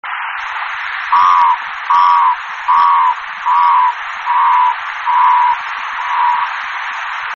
chant: chante caché entre les rochers au bord des petites criques, audible à plusieurs dizaines de mètres, diurne et nocturne:
chant guttata.mp3